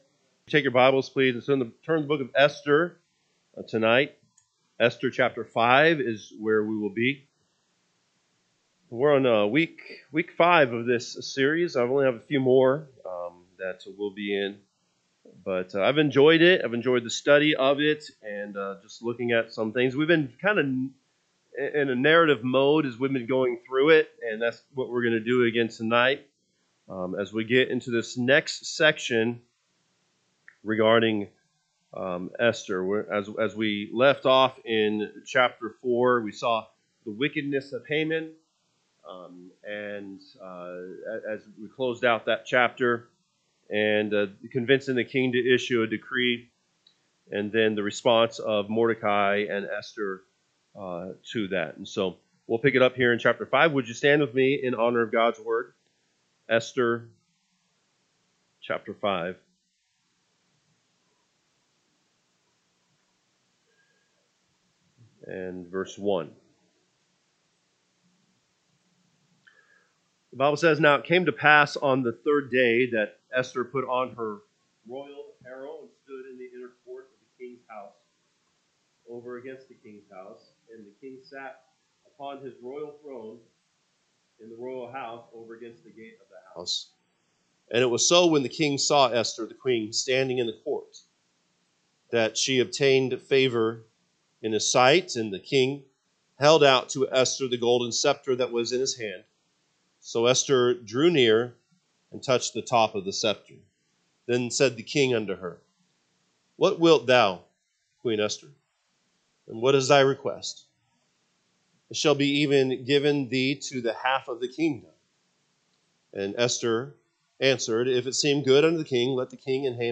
Sunday PM Message